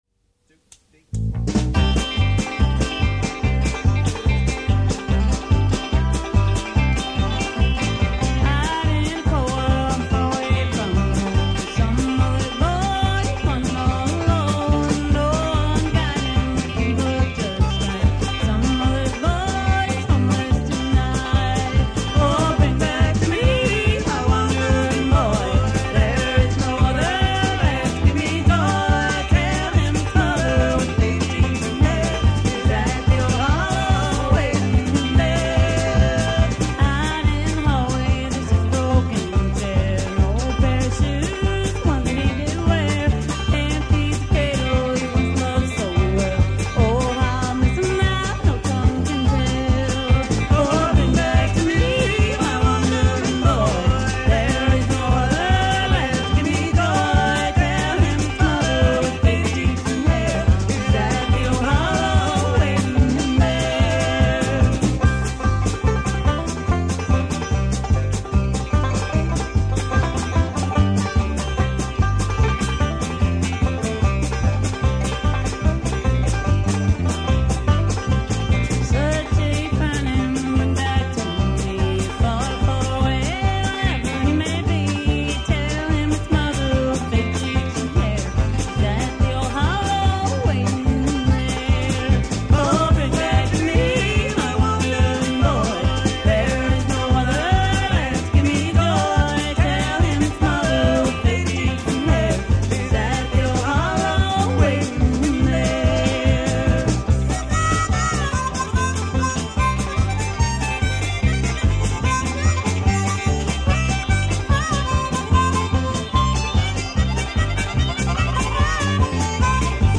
banjo, harmonica
dobro
electric guitar
electric bass
drums